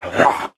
ogre2.wav